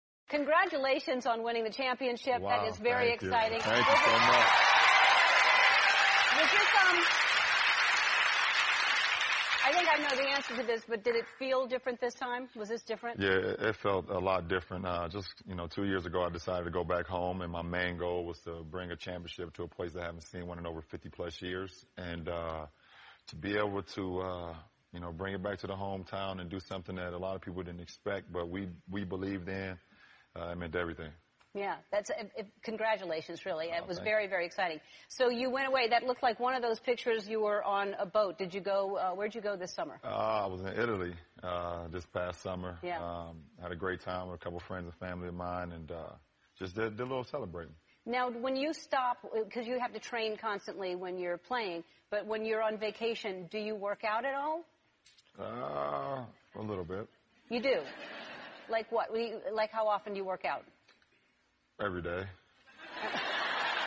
篮球英文堂 第44期:艾伦访谈詹皇(1) 听力文件下载—在线英语听力室